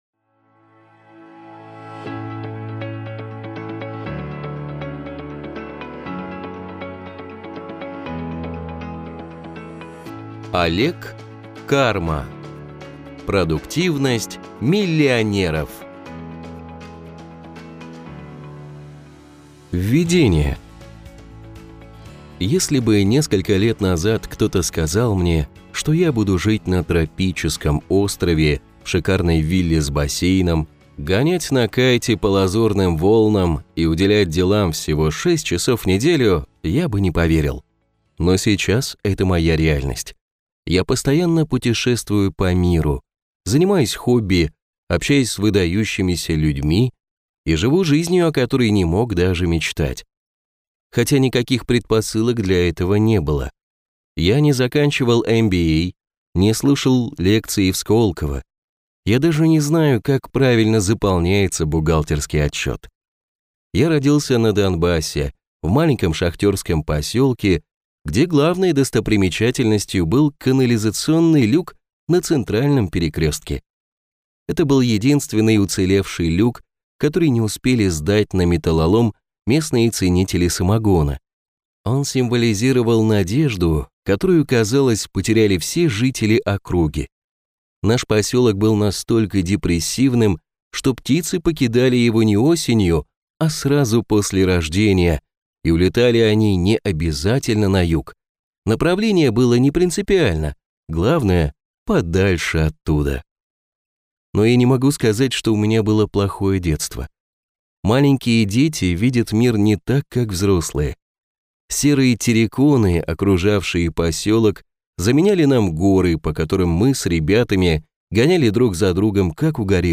Аудиокнига Продуктивность миллионеров | Библиотека аудиокниг